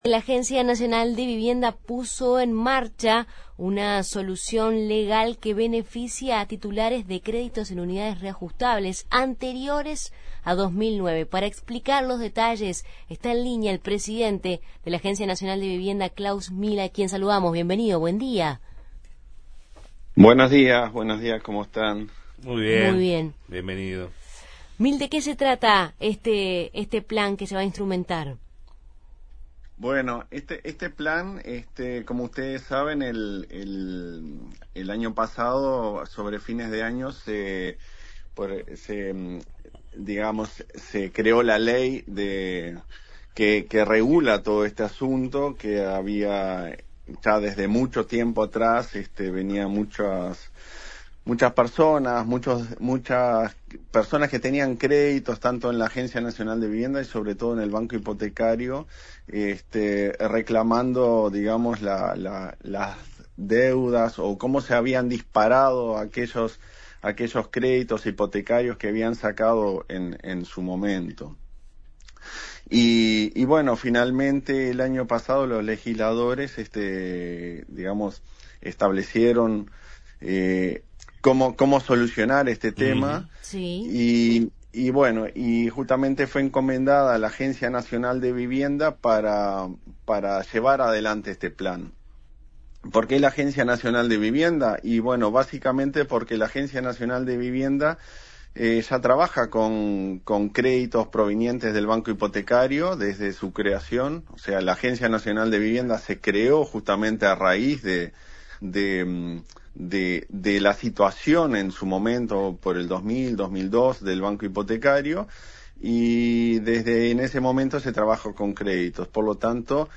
Entrevista al presidente de la Agencia Nacional de Vivienda (ANV), Klaus Mill